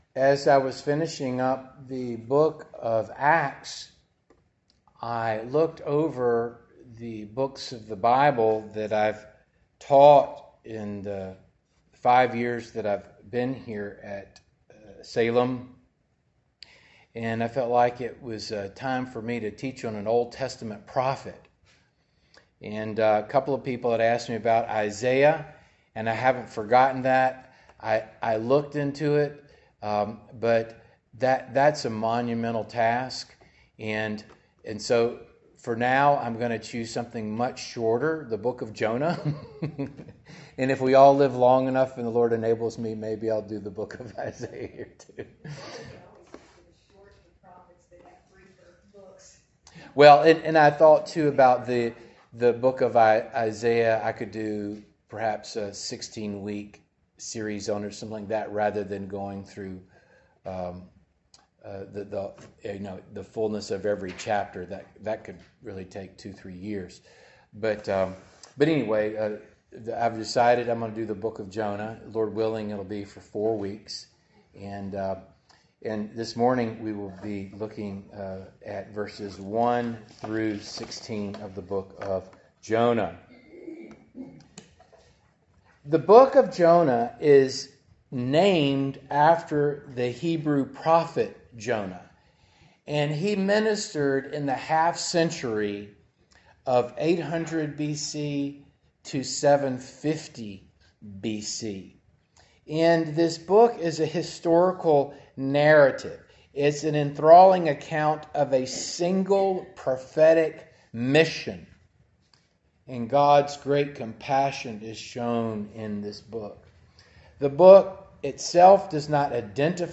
Passage: Jonah 1:1-16 Service Type: Morning Service